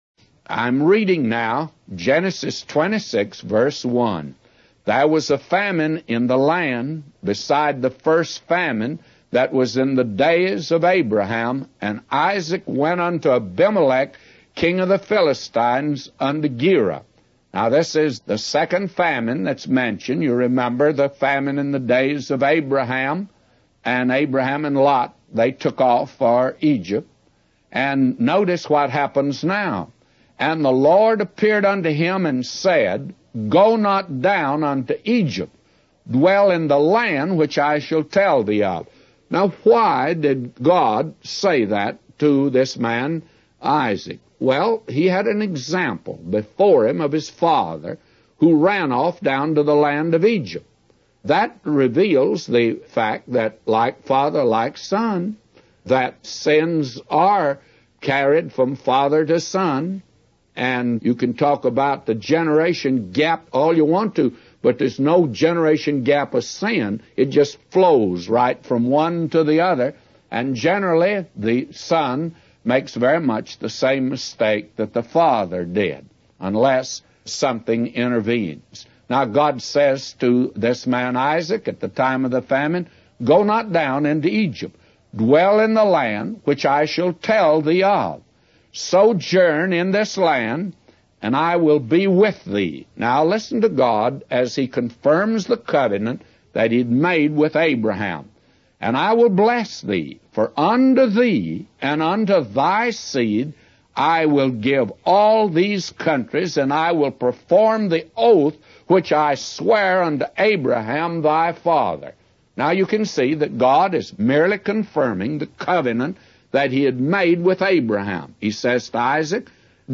In this sermon, the preacher emphasizes the importance of faith and obedience in the life of Abraham.